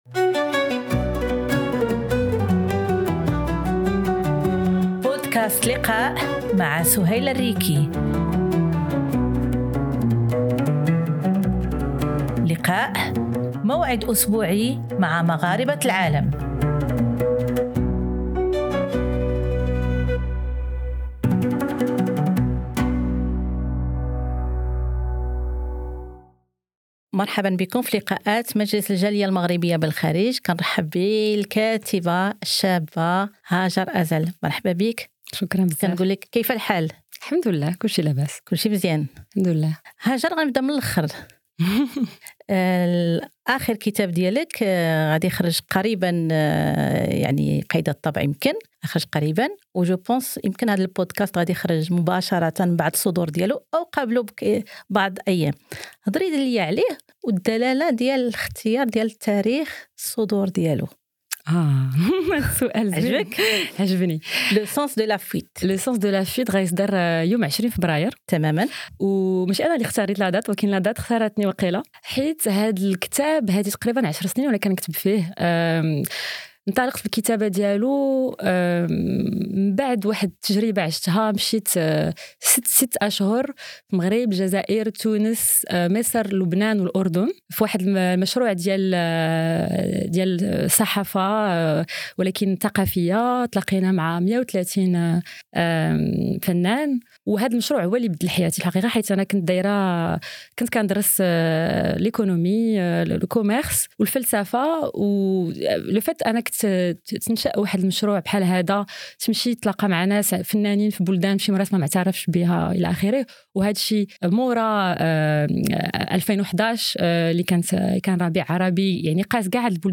لقاءات مع مغاربة العالم، بودكاست أسبوعي مع الجالية المغربية بالخارج